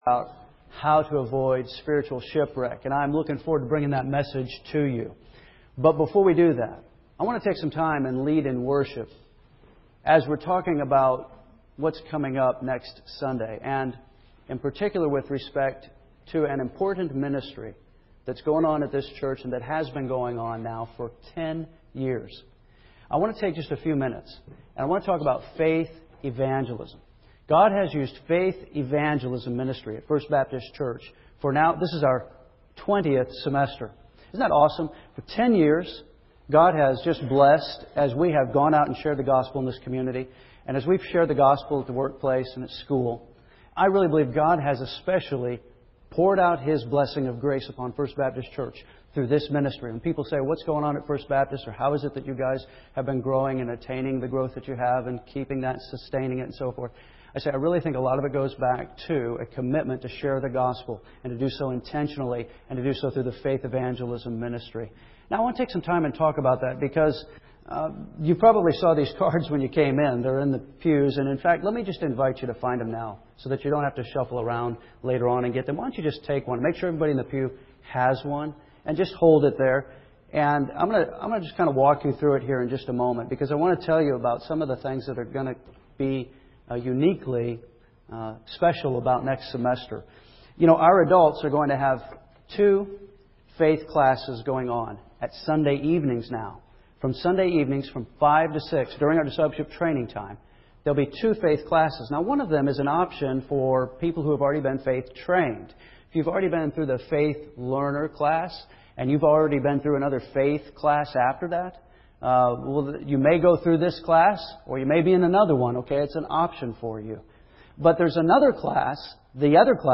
First Baptist Church Henderson KY (8-31-08) (AM)